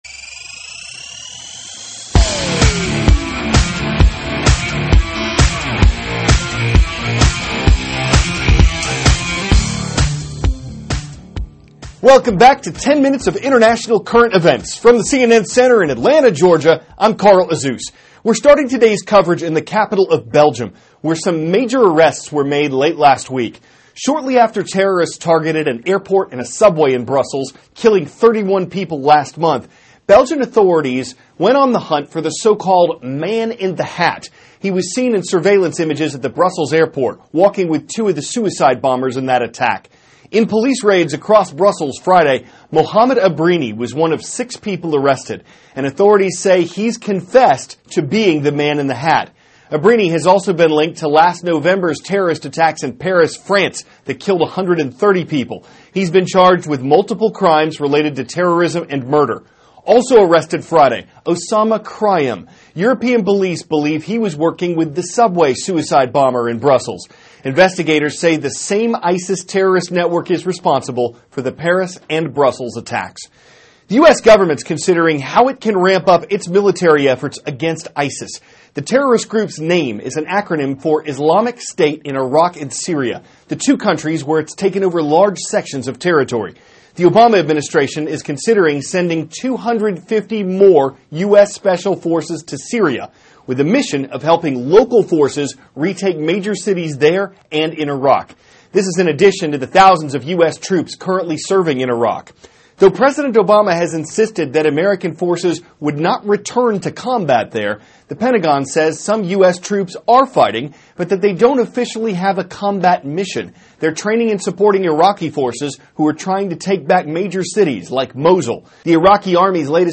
(CNN Student News) -- April 11, 2016 Terrorism Suspects Arrested in Belgium; Concerns About a Major Dam in Iraq; SpaceX Makes Historic Rocket Landing THIS IS A RUSH TRANSCRIPT.